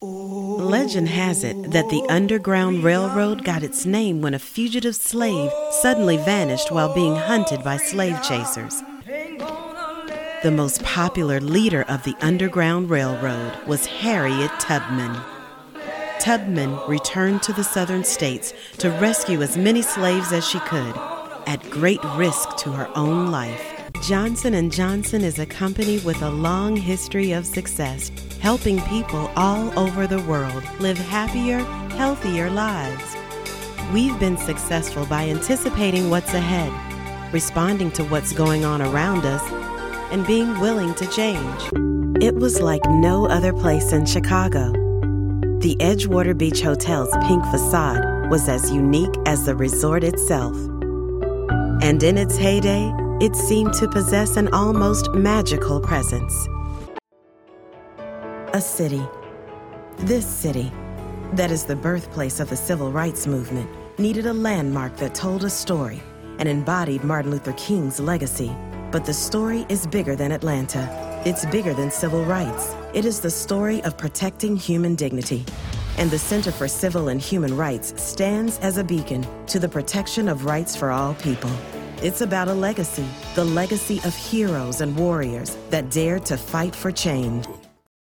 Has Own Studio
documentary